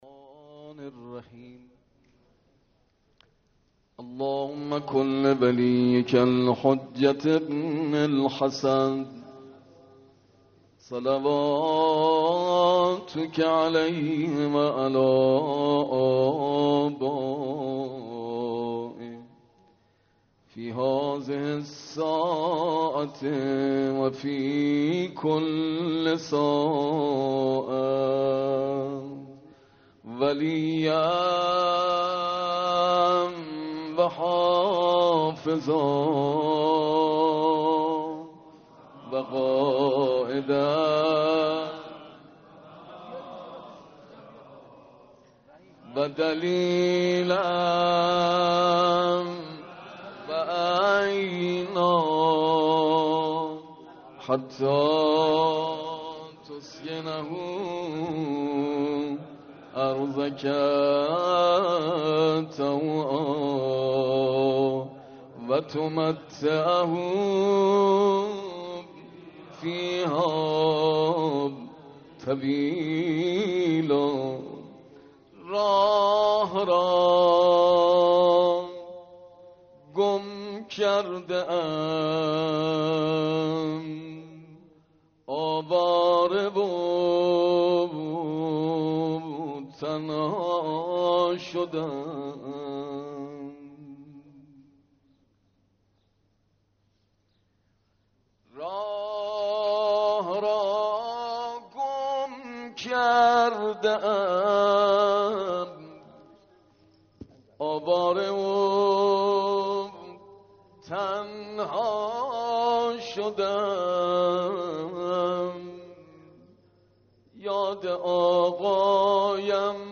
آران و بیدگل آستان امام زاده هاشم بن علی علیه السلام